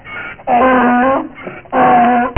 DONKEY.mp3